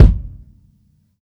soft-hitwhistle.mp3